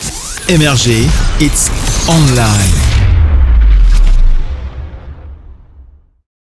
Ils sont réalisés et produits par nos équipes en interne.
JINGLE_MRG_-_HITS_ON_LINE.wav